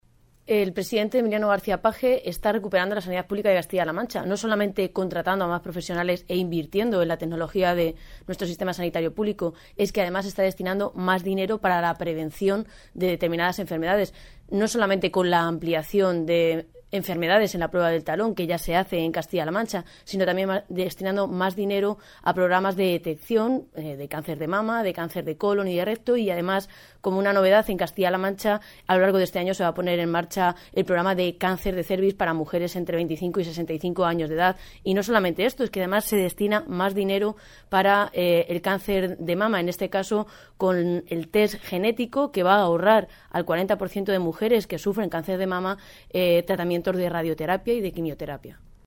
Toledo, 12 de marzo de 2017.- La diputada del Grupo socialista en las Cortes de Castilla-La Mancha, Ana Isabel Abengózar, ha lamentado las continuas “mentiras y alarma social” que lanzan los dirigentes del PP sobre la gestión sanitaria cuando “los ciudadanos y ellos mismos saben que hoy la sanidad pública está mucho mejor que la dejó Cospedal”.
Cortes de audio de la rueda de prensa